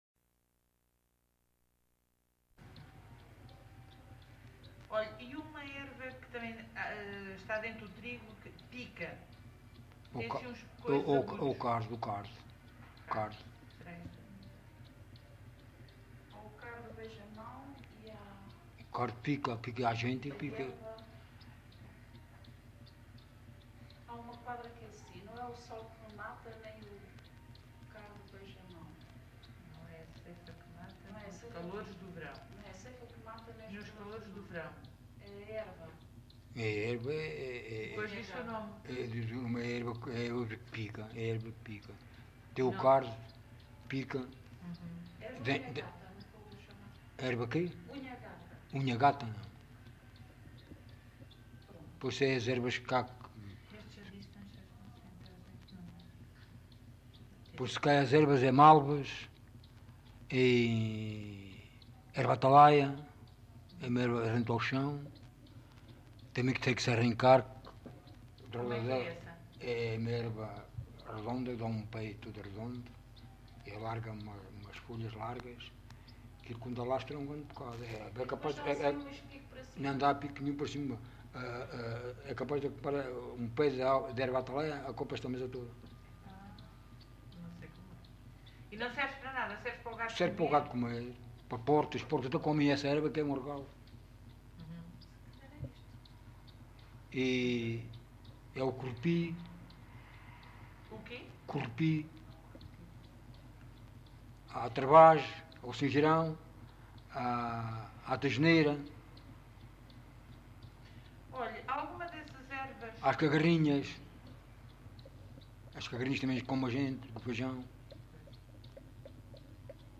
LocalidadeAlcochete (Alcochete, Setúbal)